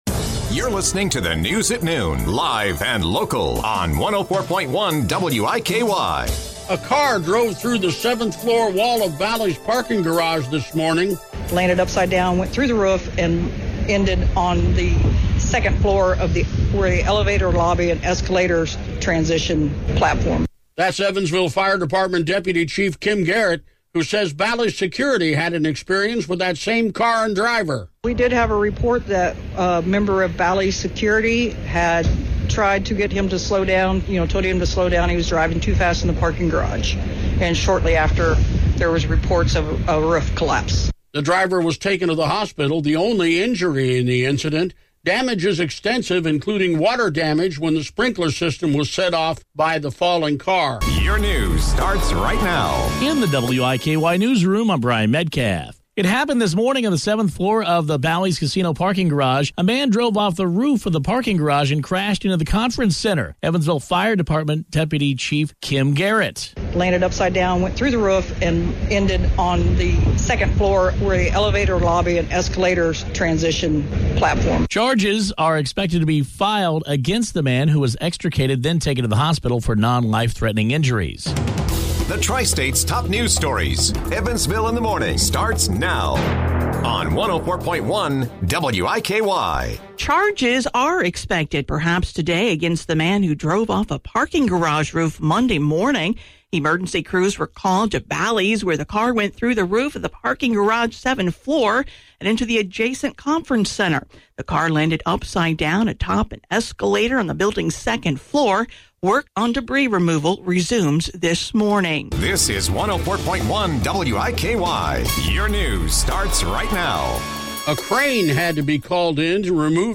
5. Best Radio General News StoryWIKY-FM (Evansville) – Car Crashes at Bally’s
Designed to honor the work of a general assignment reporter, this includes coverage of a single news story, gathered and aired in the same day.